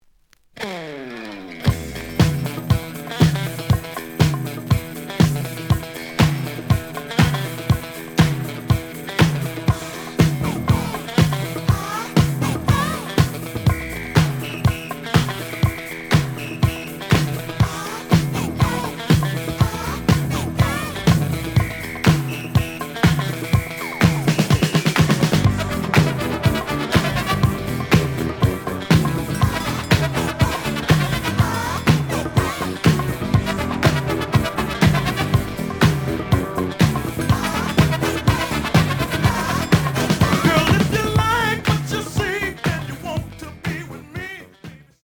The audio sample is recorded from the actual item.
●Genre: Funk, 80's / 90's Funk